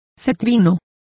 Complete with pronunciation of the translation of sallow.